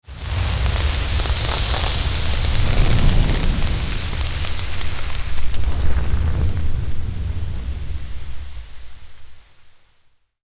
deep_fry.wav